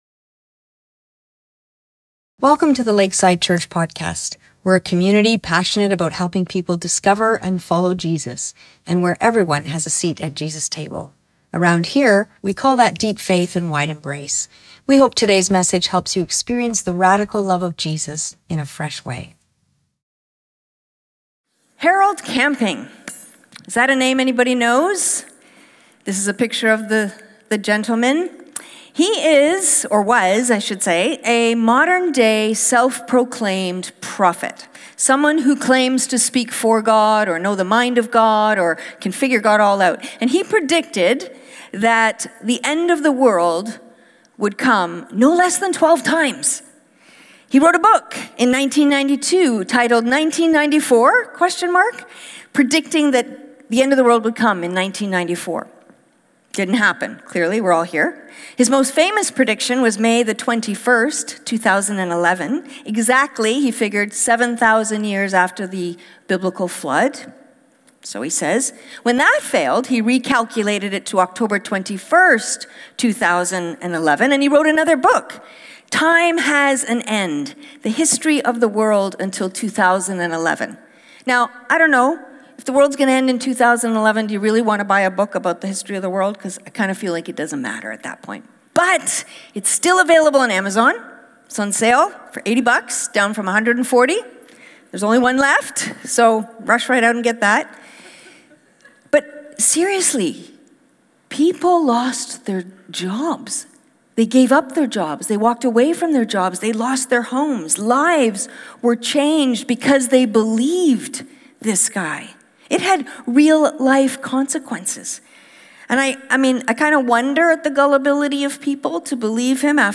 SermonOnTheMount_PolishedButPoisoned.mp3